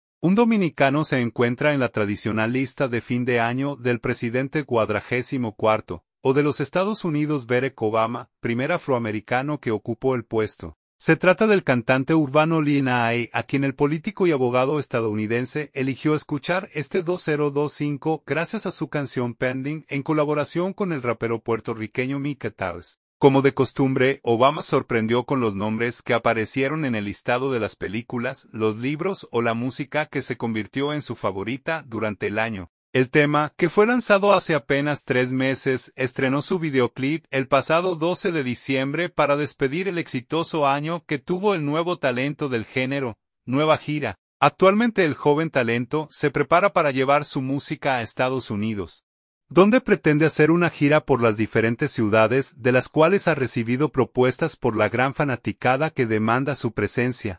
cantante urbano
rapero puertorriqueño